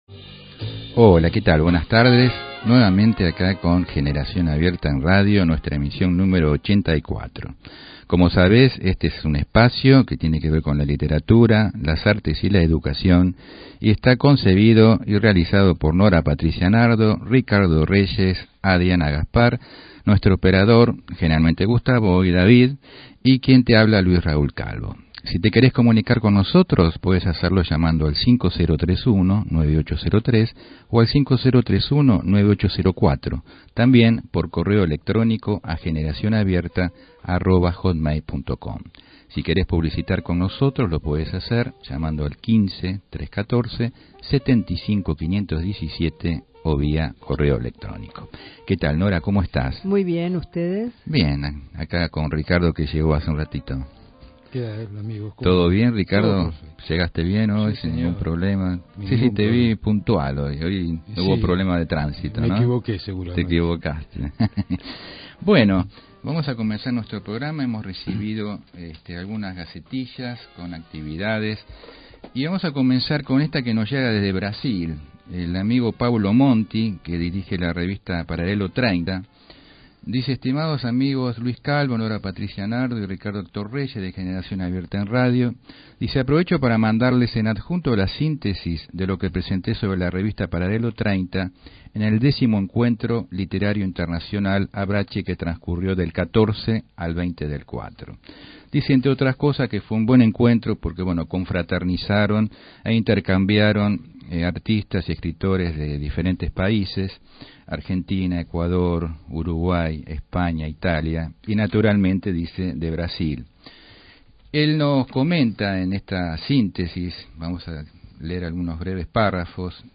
Diálogo en vivo en el estudio